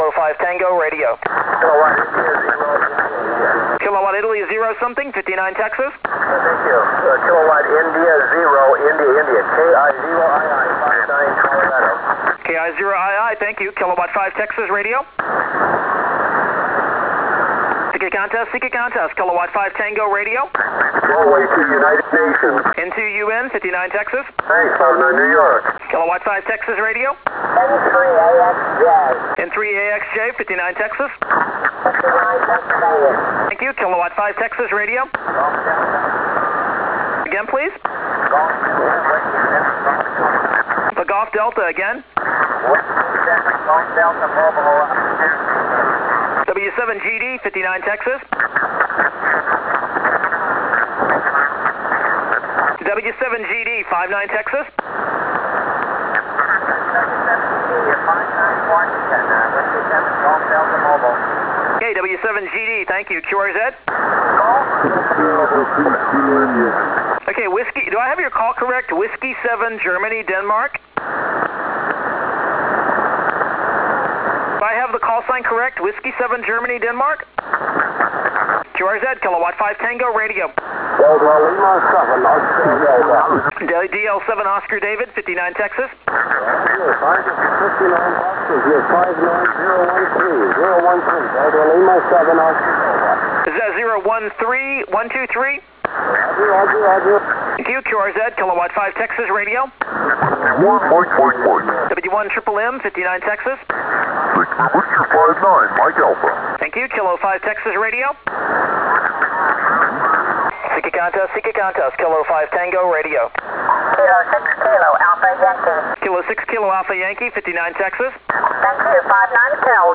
My fastest 10 minutes from the 1999 ARRL 10m contest.
The above sound pretty good, this one is a lower quality recording.